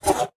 Select Scifi Tab 13.wav